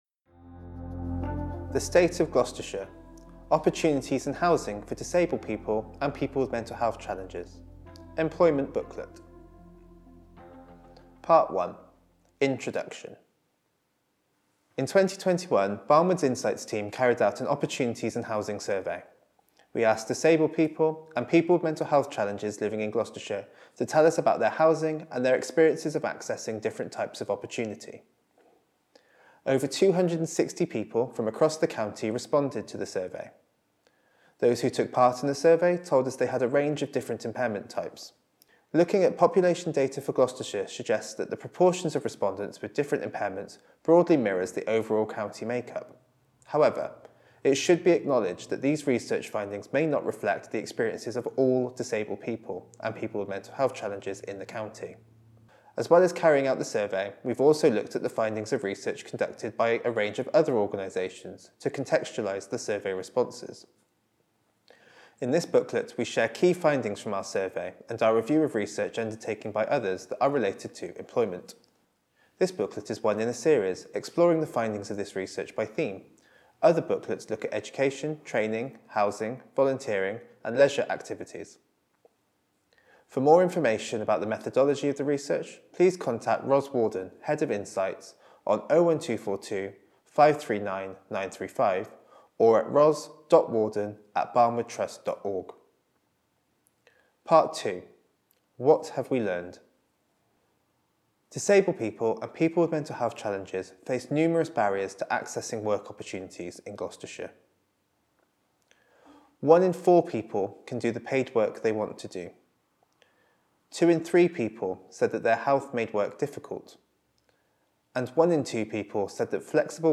British Sign Language (BSL) translation Subtitled video of the report being read by one of our Researchers Audio recording of the report being read by one of our Researchers Easy Read version to read or download Large print version to read or download